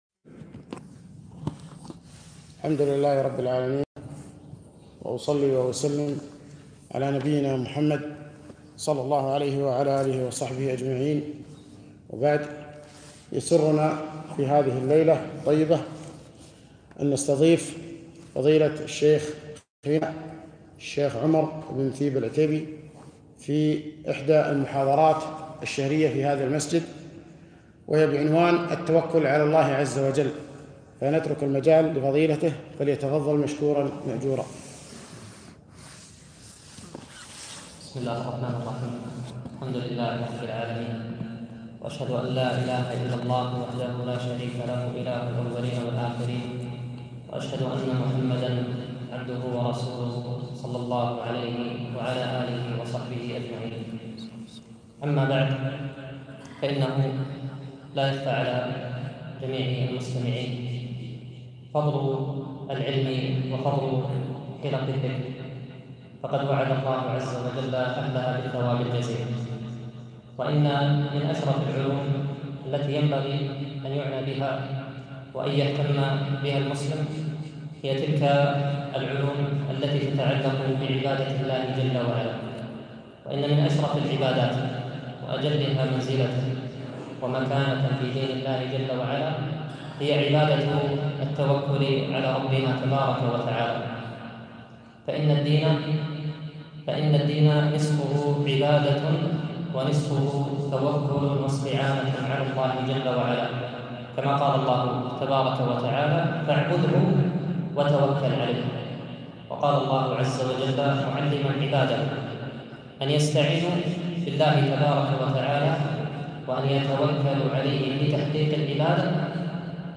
محاضرة - التوكل على الله عزوجل